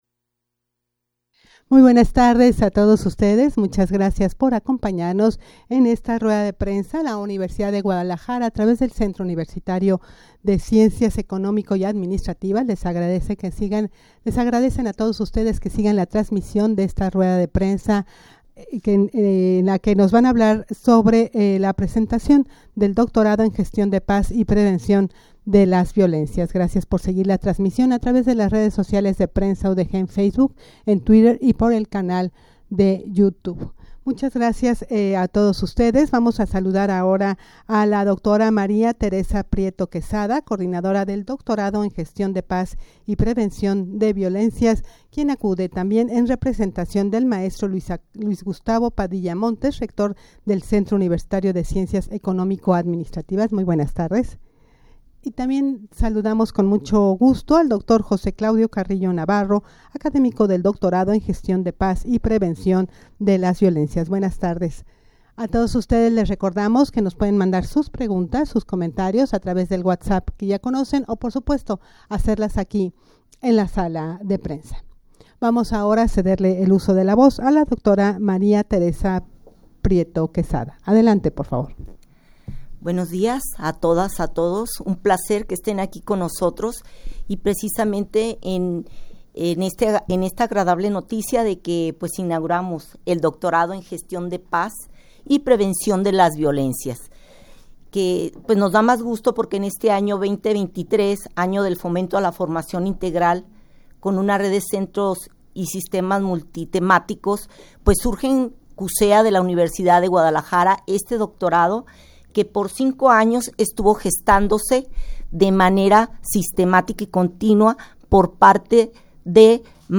rueda-de-prensa-presentacion-del-doctorado-en-gestion-de-paz-y-prevencion-de-las-violencias.mp3